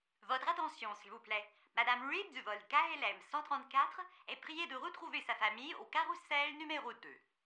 描述：Airport announcers female and male, mostly French (some English and Spanish).
标签： publicaddress localizationassets airport announcer
声道立体声